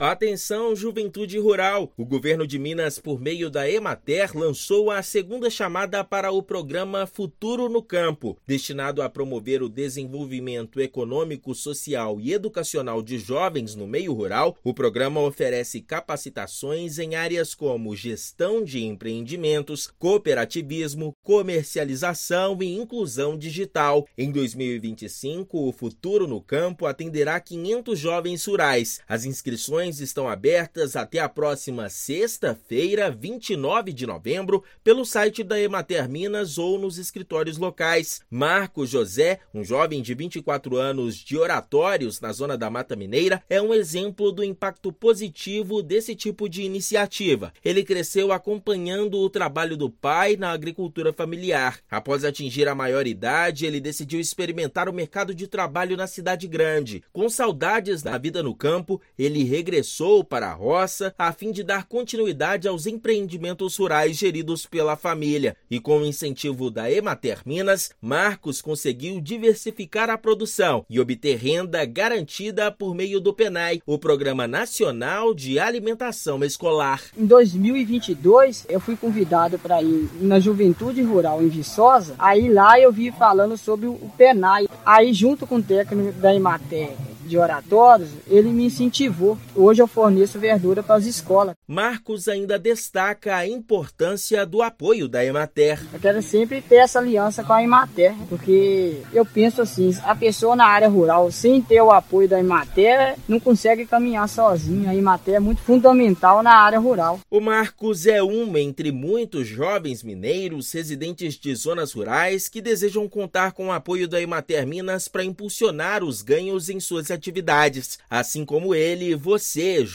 O programa do Governo de Minas, por meio da Empresa de Assistência Técnica e Extenção Rural do Estado de Minas Gerais (Emater-MG), oferece capacitações em gestão, cooperativismo, comercialização e inclusão digital para jovens rurais. Ouça matéria de rádio.